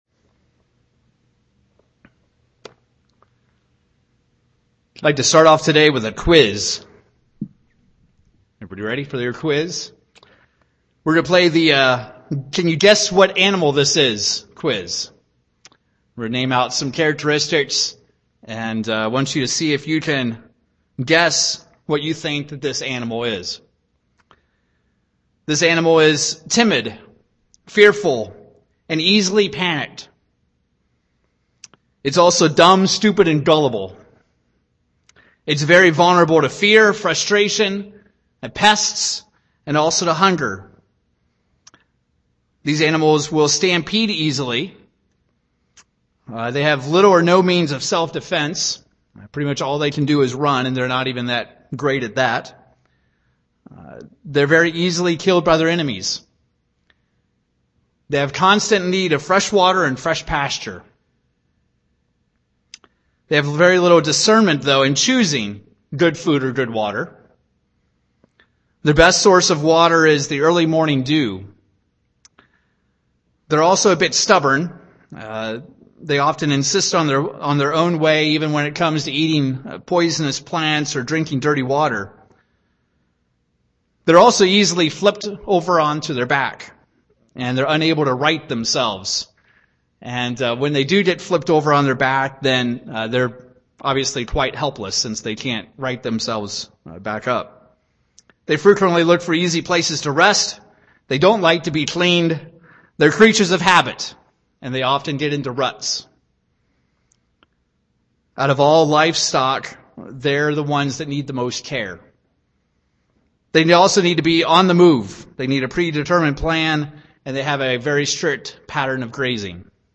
Given in Cincinnati East, OH Wichita, KS